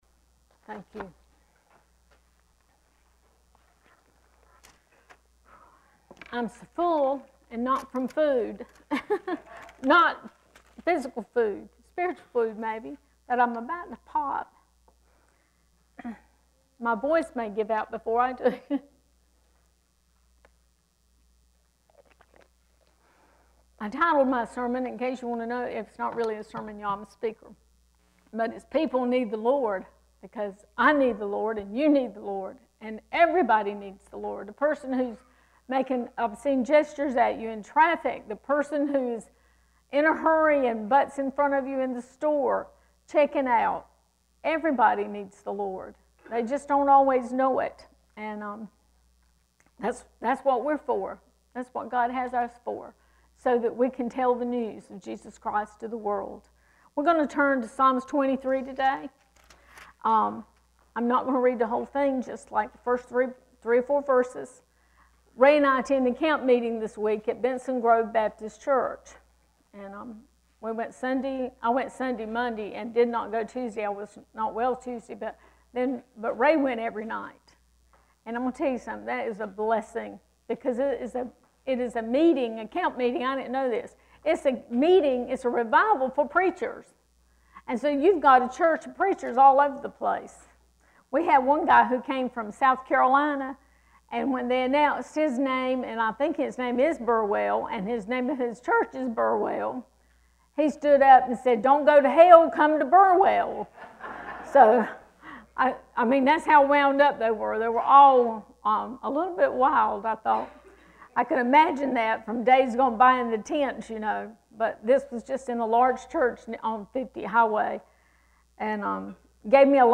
This weeks message: